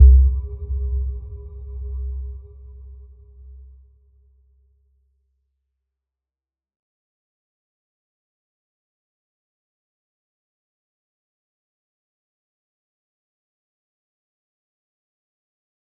Little-Pluck-C2-mf.wav